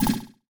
Message Bulletin Echo 11.wav